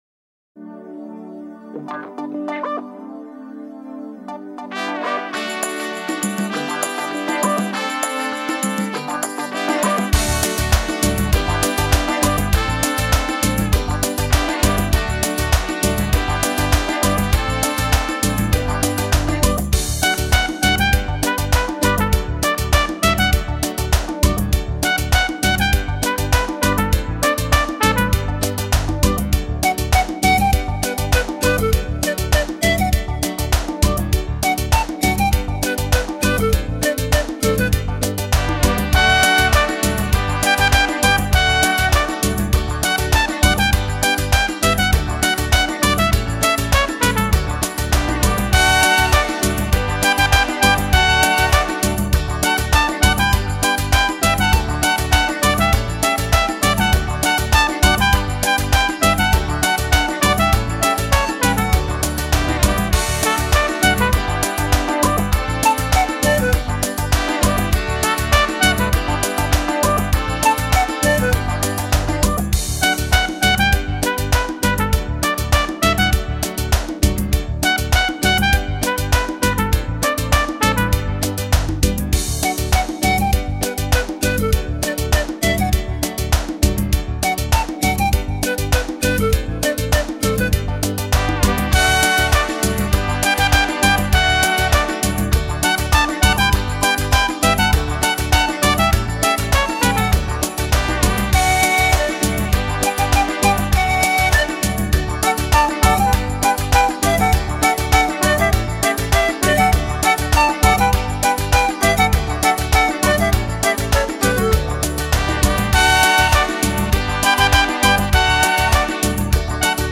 陶笛晶莹剔透的音色、排箫飘逸独特的风格和淳厚低沉的伴奏构成了这一独特的组合。